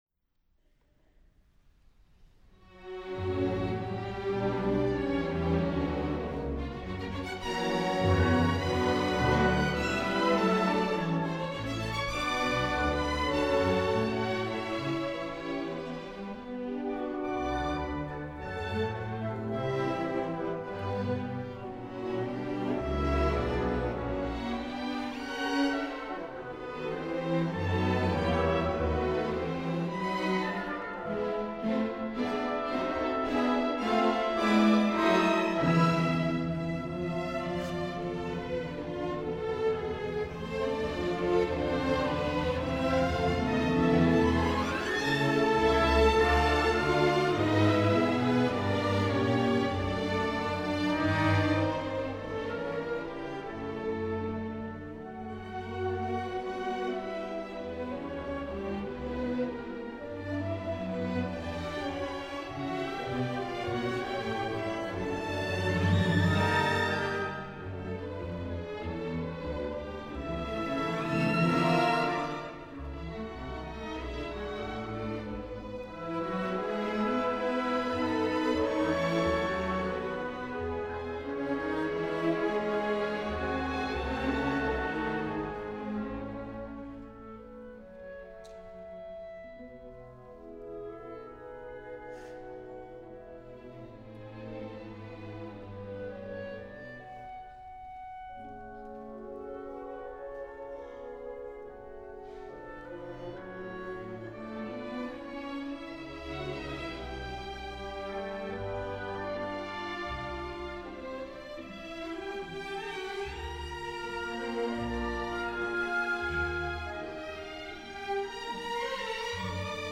Edward Elgar: Concierto para violín y orquesta en si menor, op. 61